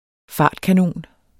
Udtale [ -kaˌnoˀn ]